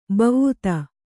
♪ bavvuta